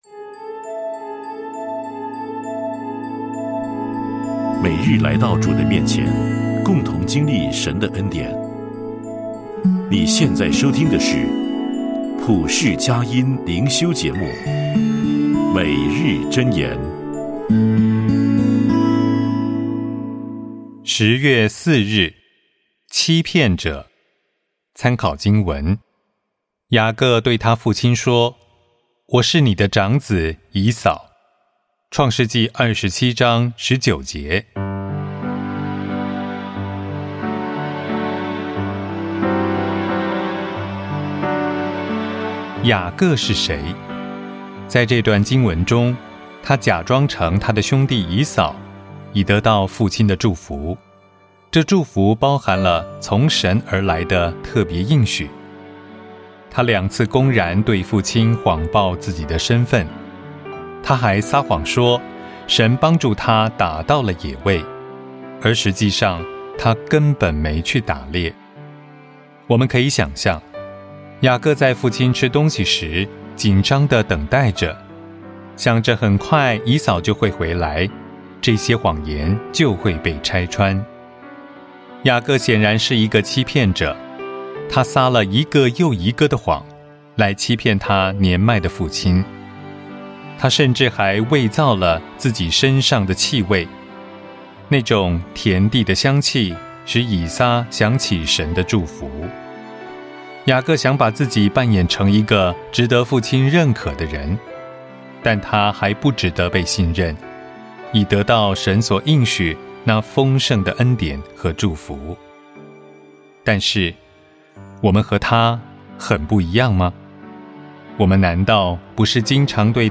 诵读
片头